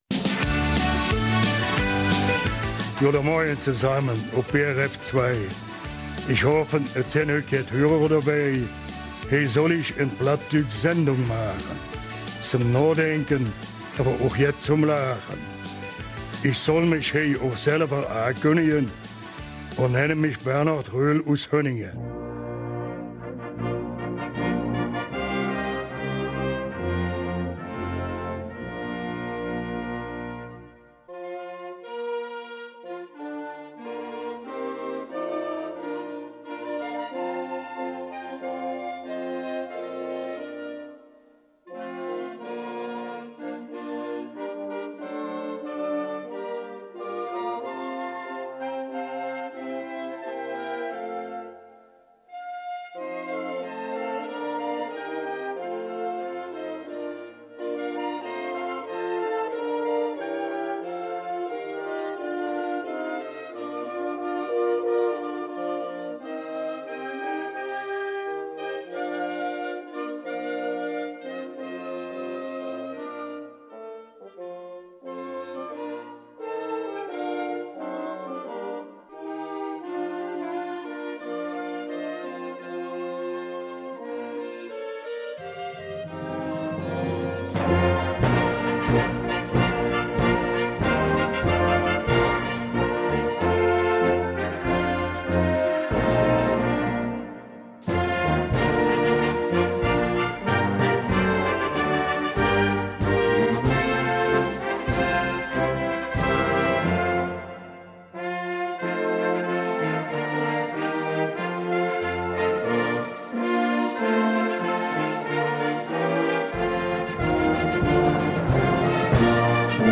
Eifeler Mundart zum Start in die Sommerferien